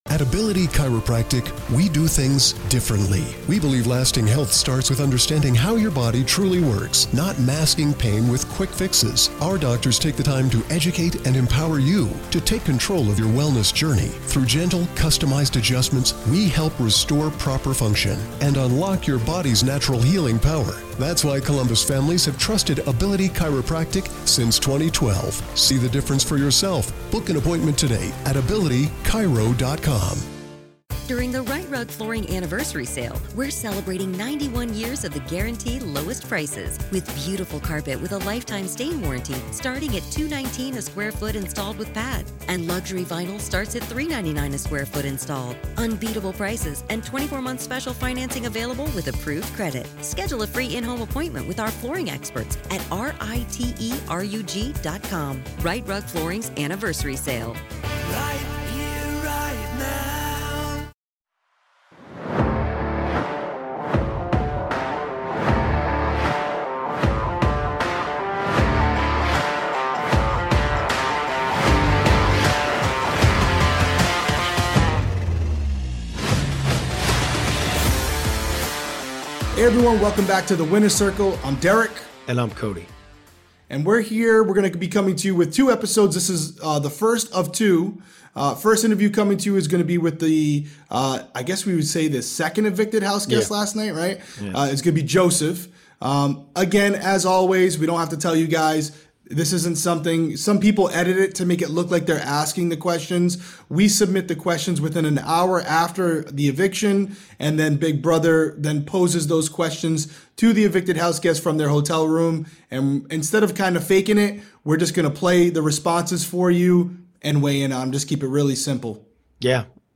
Exit Interview